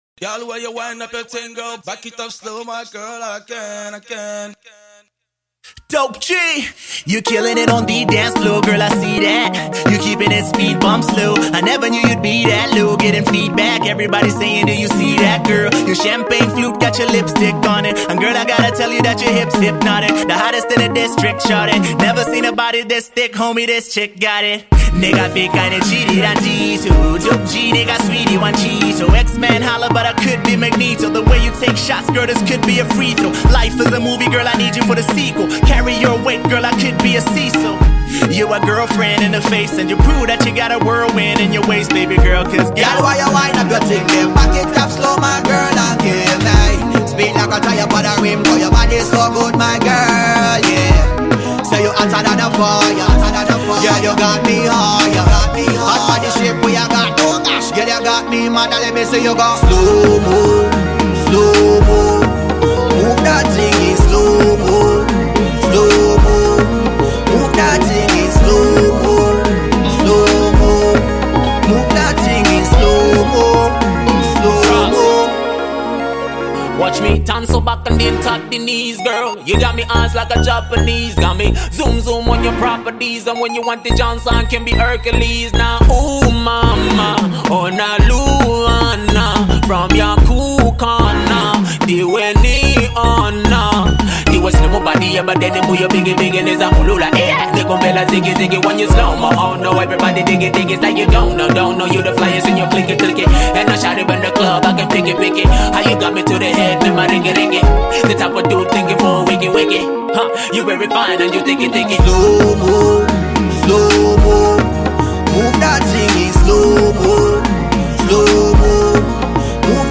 Zambian Hip-Hop group